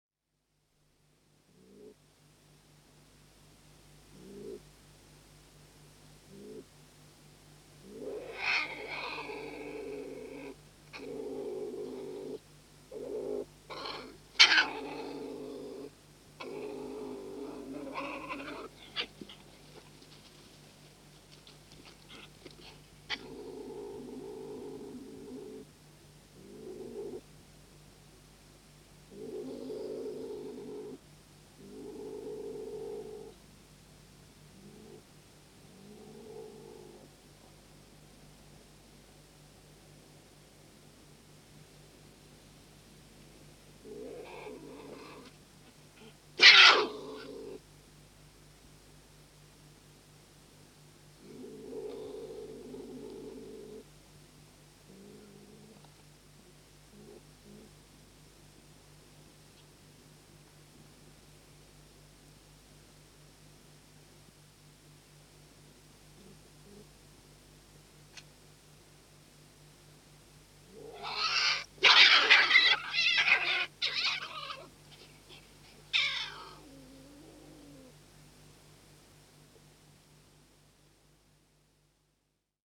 Звуки куницы
Записано на шотландской земле